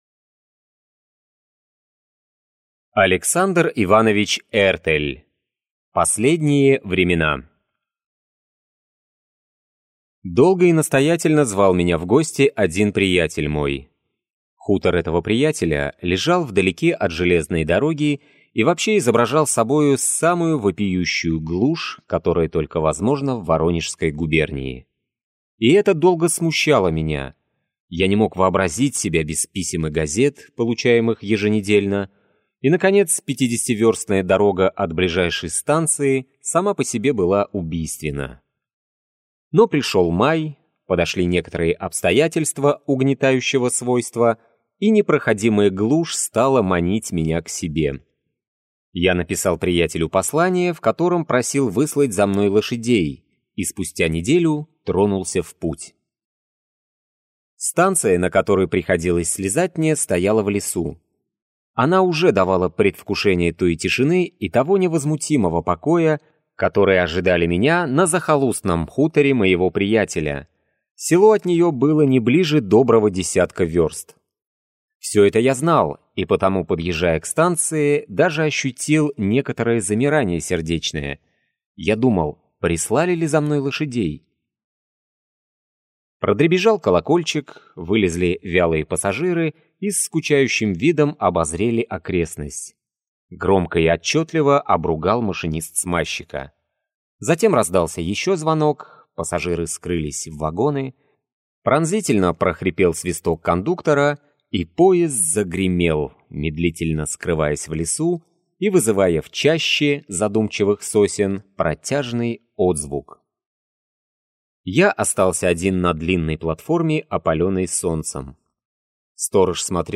Аудиокнига Последние времена | Библиотека аудиокниг
Прослушать и бесплатно скачать фрагмент аудиокниги